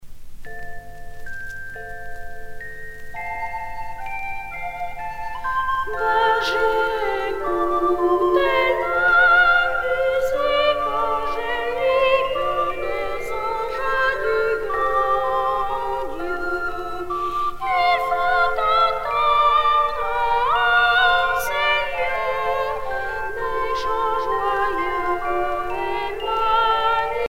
circonstance : Noël, Nativité
Genre strophique Artiste de l'album Petits chanteurs de Notre-Dame-de-la-Joie (les)
Pièce musicale éditée